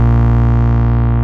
VEC1 Bass Long 10 B.wav